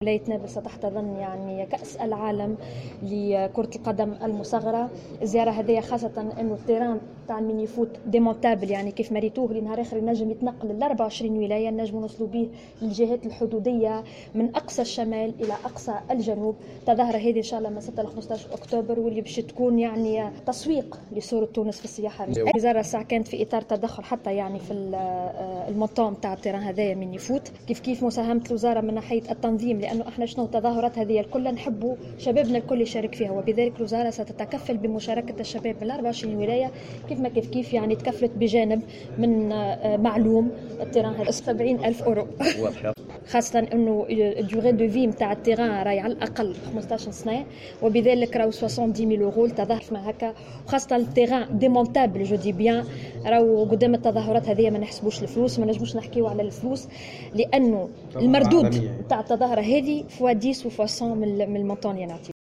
ماجدولين الشارني : وزيرة شؤون الشباب والرياضة تحميل المشاركة علي مقالات أخرى وطنية 01/12/2025 اليوم..